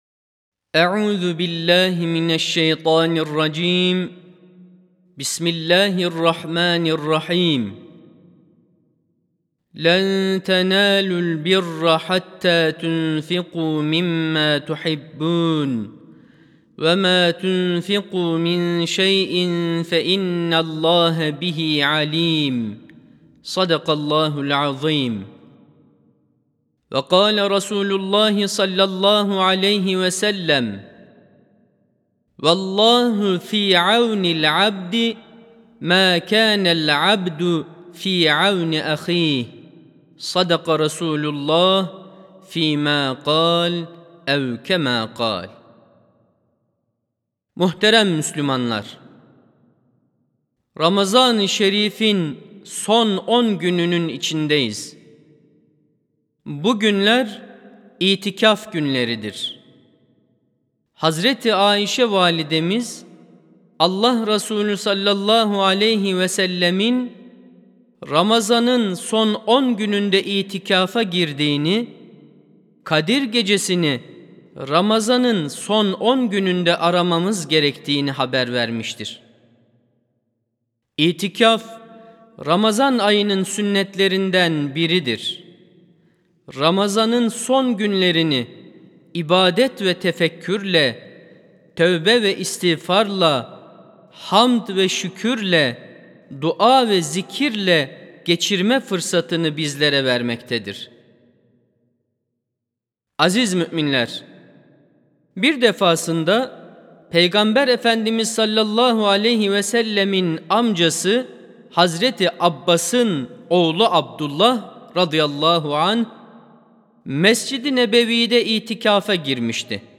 Hutbeler
Sesli Hutbe (İnfak ve Kur'an Ayı Ramazan).mp3